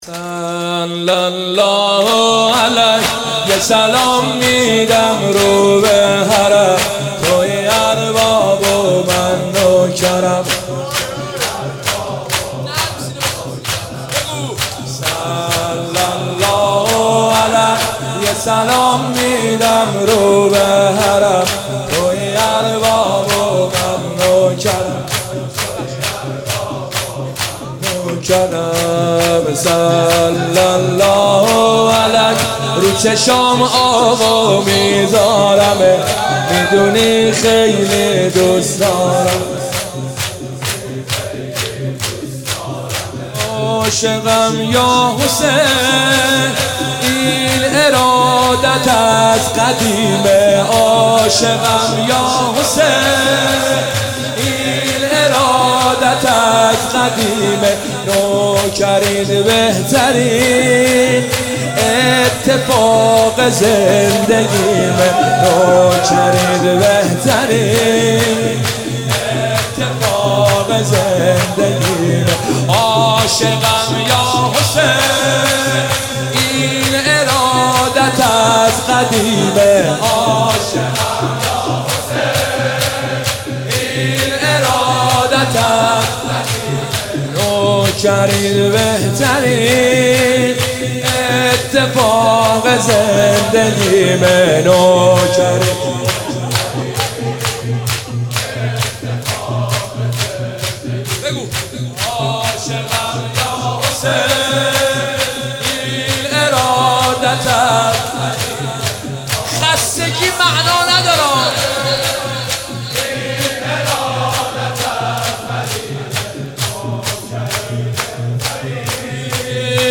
قالب : شور